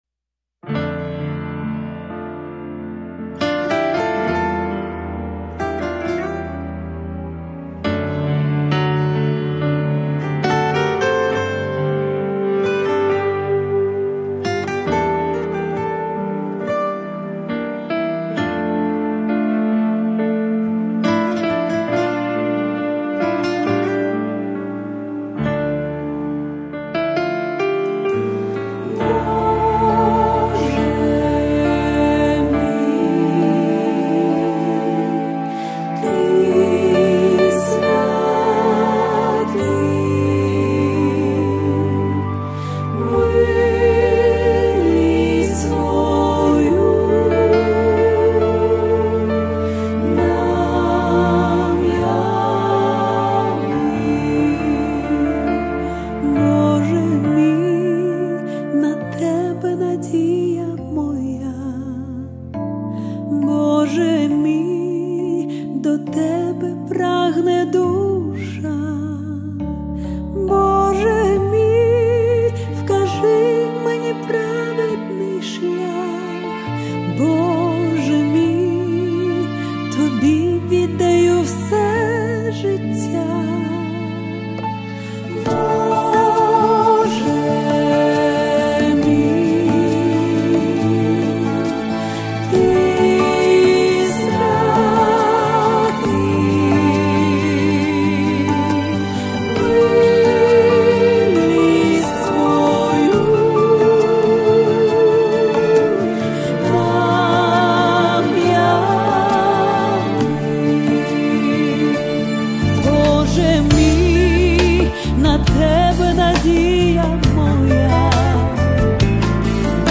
493 просмотра 666 прослушиваний 40 скачиваний BPM: 70